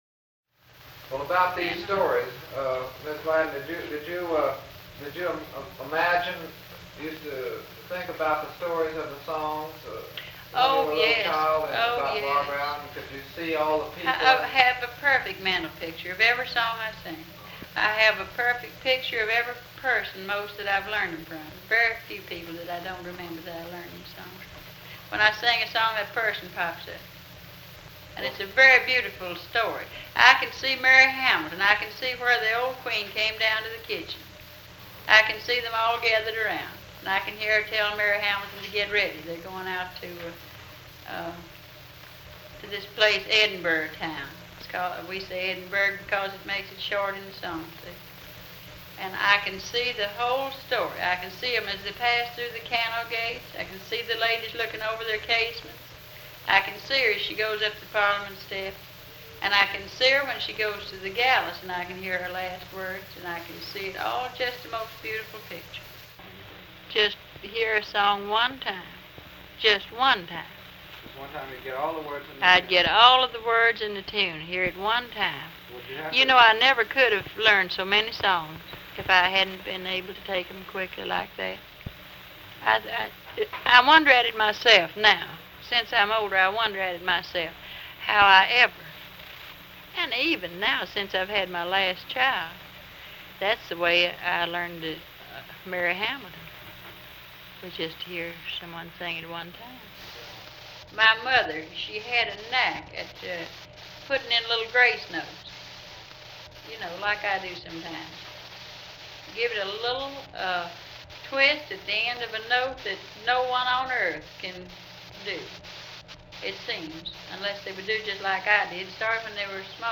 03 Mental Pictures (Interview).mp3: